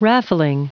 Prononciation du mot raffling en anglais (fichier audio)
Prononciation du mot : raffling